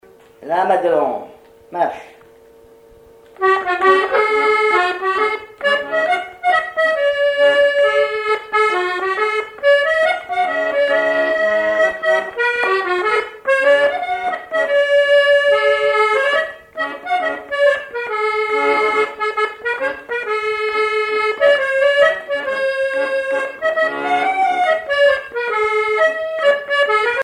Mémoires et Patrimoines vivants - RaddO est une base de données d'archives iconographiques et sonores.
accordéon(s), accordéoniste
Répertoire à l'accordéon chromatique
Pièce musicale inédite